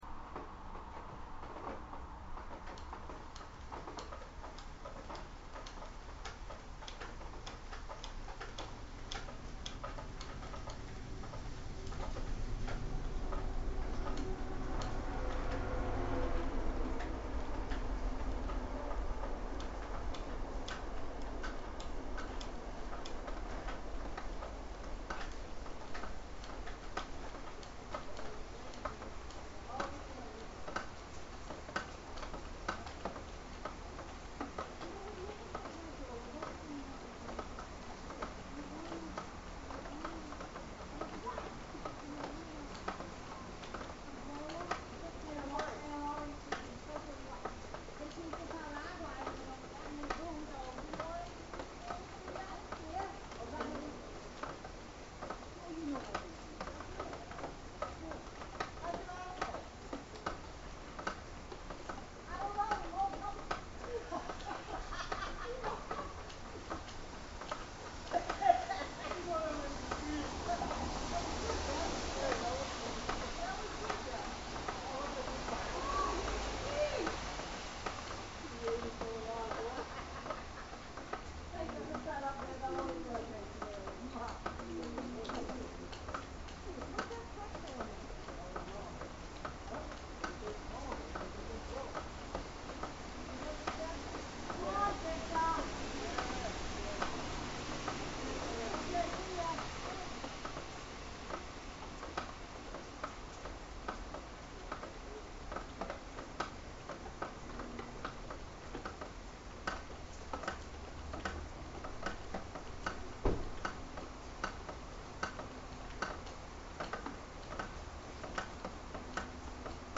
Rain, Commuter Rail, Laughter, and the Mass Pike
This is a recording of rain tapping rhythmically on a metal windowsill in Boston, Massachusetts. Outside the window the Framingham/Worcester commuter rail rumbles by, people laugh, and the Mass Pike (aka interstate 90) seethes with heavy traffic.